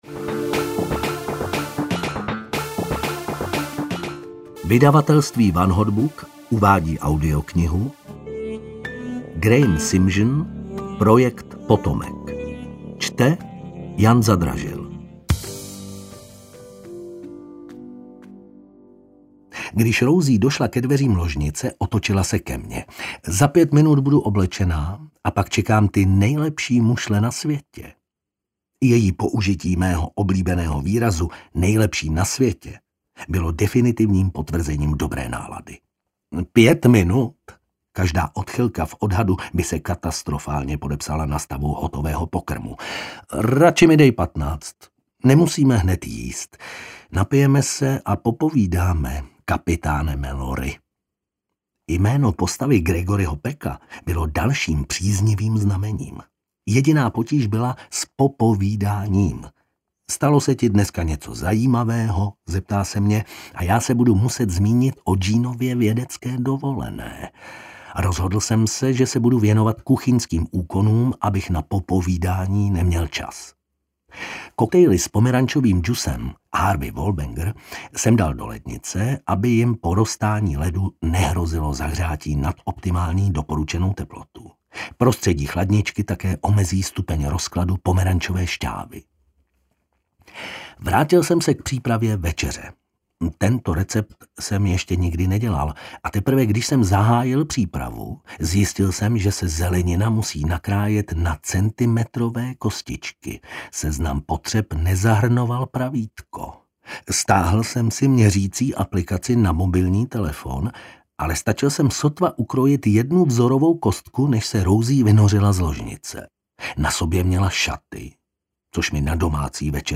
Projekt potomek audiokniha
Ukázka z knihy